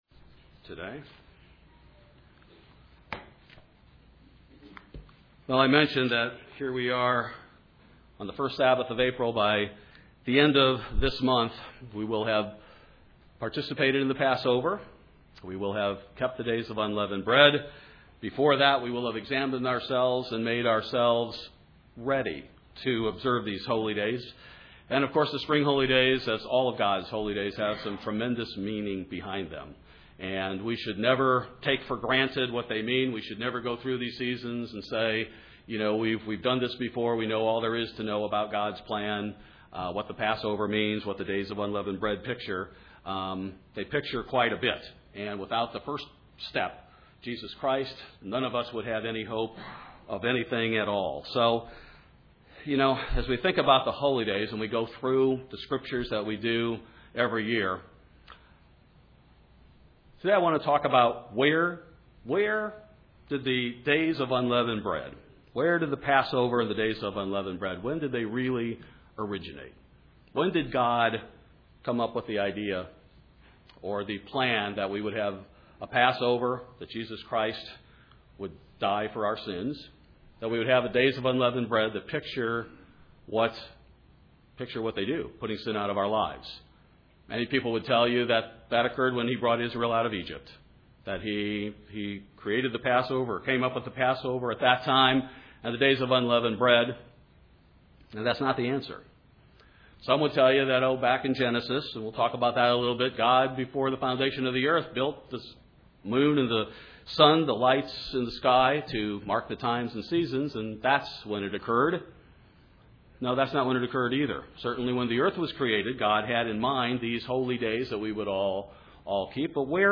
This message was given during the Days of Unleavened Bread.